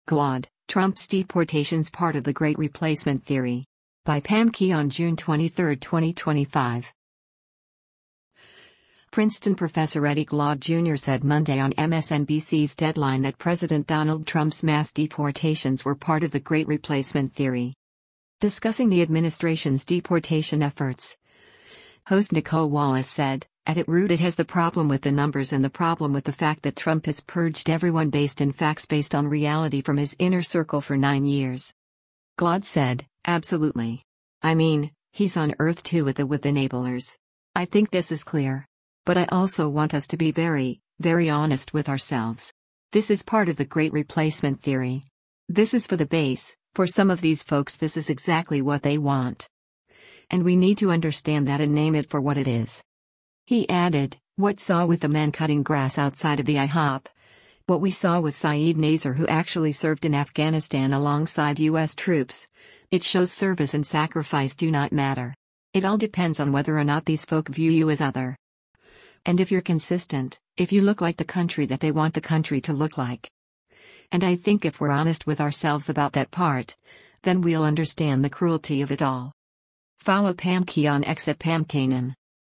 Princeton professor Eddie Glaude Jr. said Monday on MSNBC’s “Deadline” that President Donald Trump’s mass deportations were “part of the great replacement theory.”